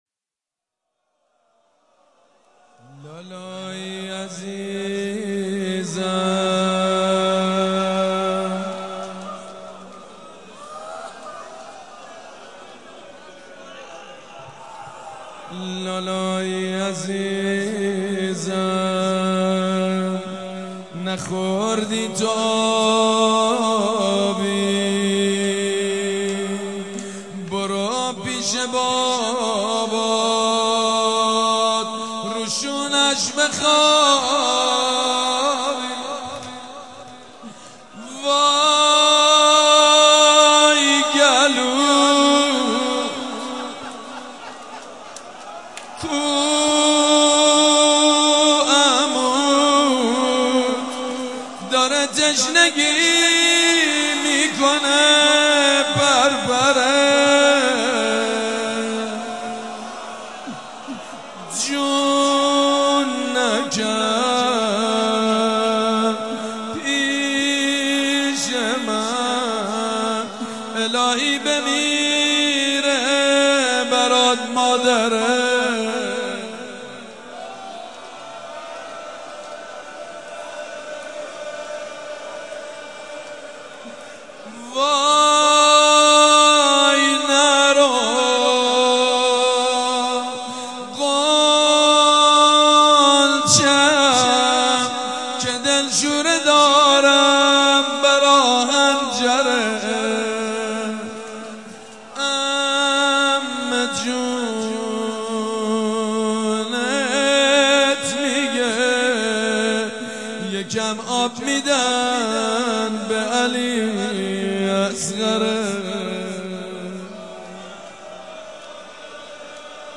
لالایی عزیزم نخوردی تو آبی _ روضه سيد مجيد بنی فاطمه شب هفتم محرم 96/07/5